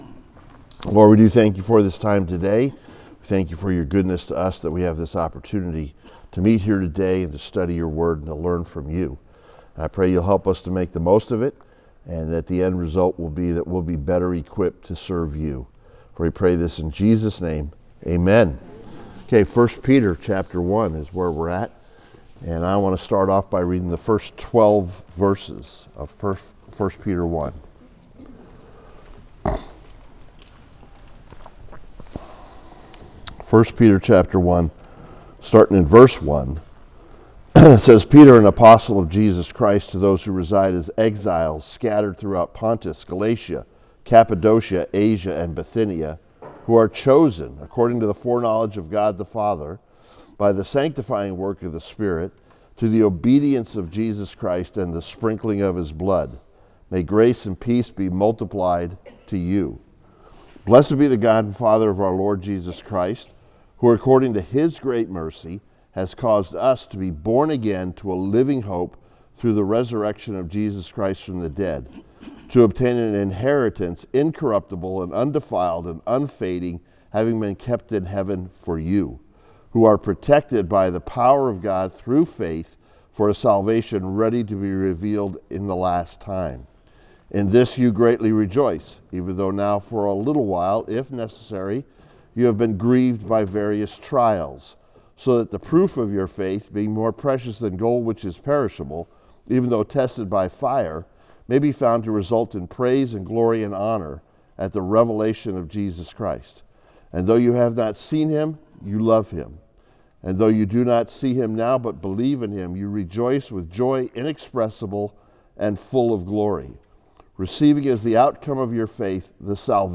Equipped to Stand Firm: The Results of Faith (Sermon) - Compass Bible Church Long Beach